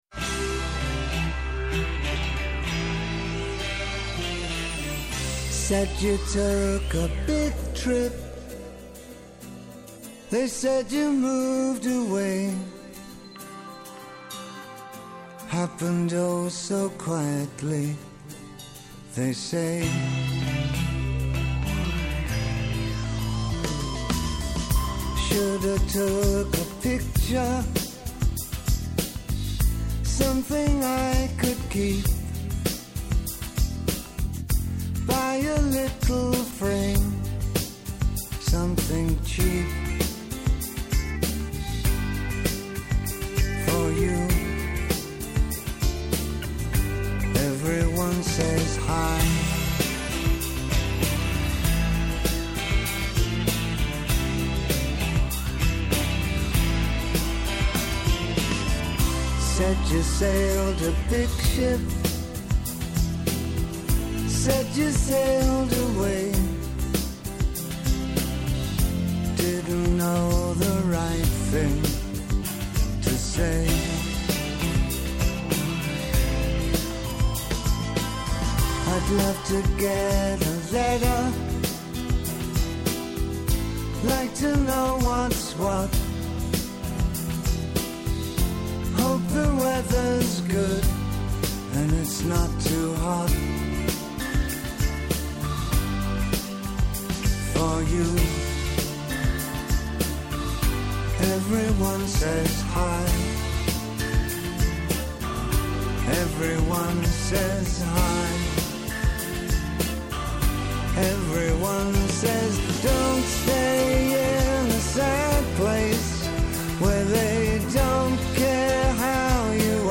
Kαλεσμένοι στην εκπομπή είναι κυβερνητικοί αξιωματούχοι, επιχειρηματίες, αναλυτές, τραπεζίτες, στελέχη διεθνών οργανισμών, πανεπιστημιακοί, φοροτεχνικοί και εκπρόσωποι συνδικαλιστικών και επαγγελματικών φορέων, οι οποίοι καταγράφουν το σφυγμό της αγοράς και της οικονομίας.
Συνεντεύξεις